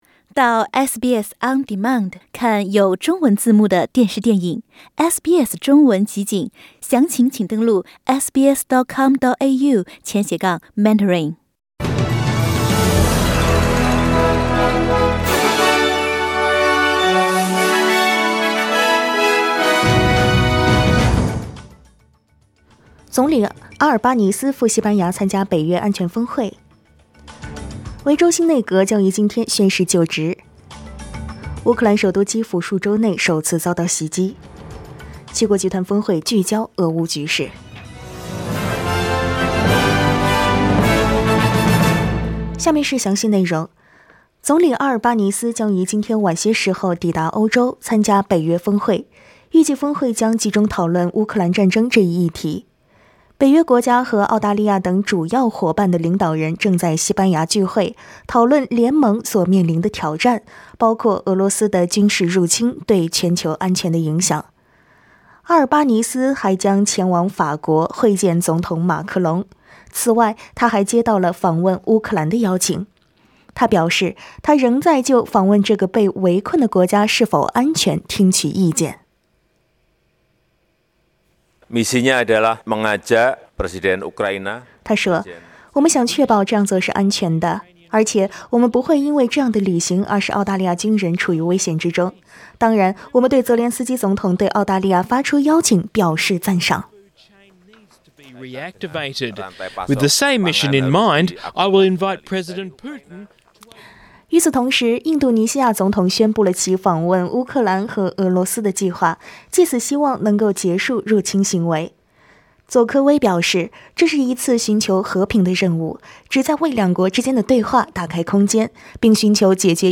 SBS早新闻（6月27日）
SBS Mandarin morning news Source: Getty Images